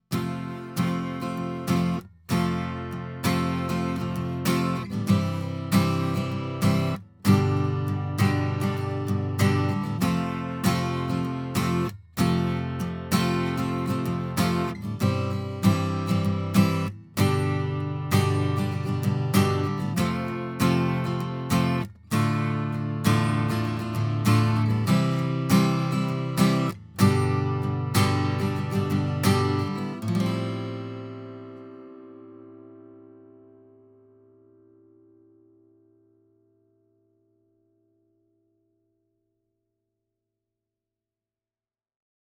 Der Sound der Baton Rouge X11S/OME ist ganz dem Motto der 20er Jahre entsprechend.
Der Klang der Baton Rouge X11S/OME ist in den Mitten sehr ausgeprägt, unser vorliegendes Testmodell schwächelt hingegen etwas im Bass, was wohl ein Resultat der kleineren Bauweise ist.
Alleine gespielt kann die Gitarre hingegen etwas dünn klingen.
baton_rouge_ome__06__oc818_strummed.mp3